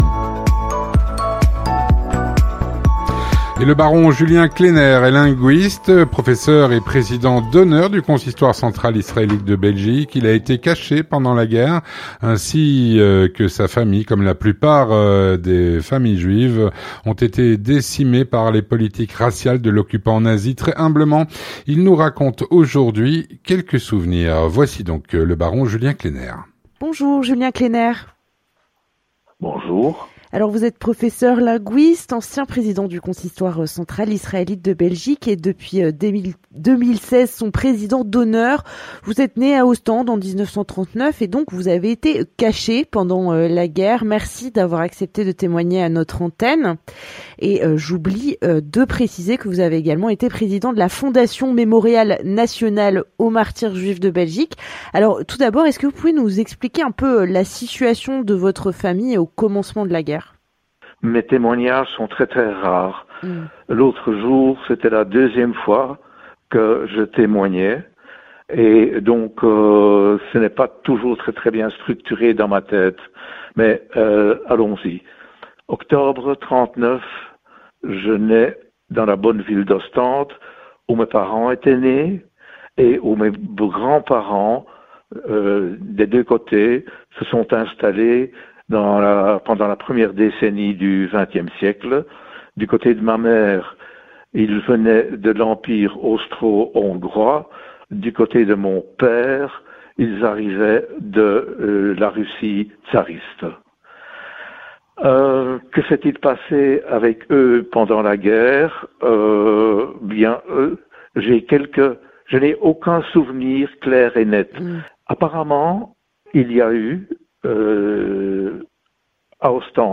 Très humblement, il nous raconte quelques souvenirs.